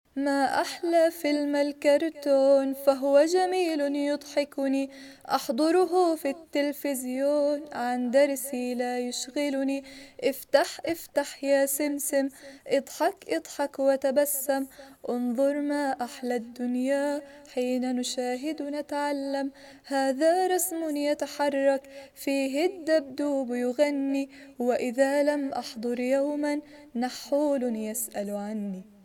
انشد فلم الكرتون عربي صف اول فصل اول منهاج اردني